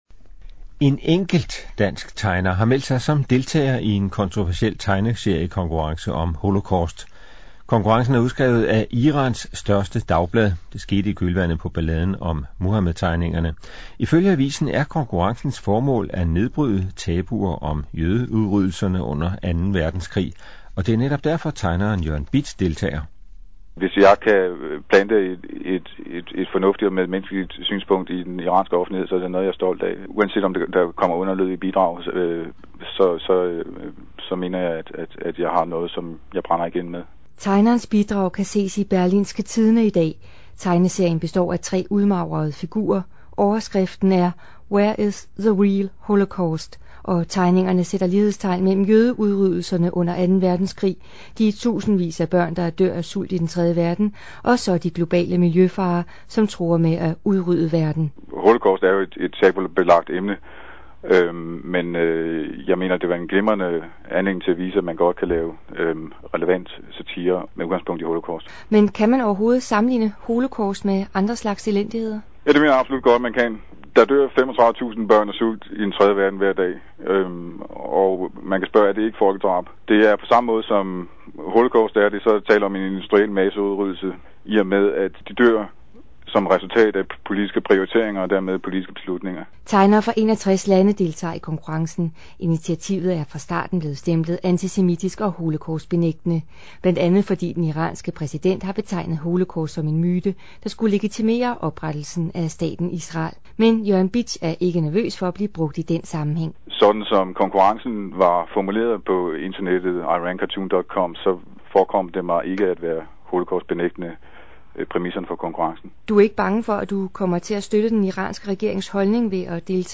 Radioindslag 2006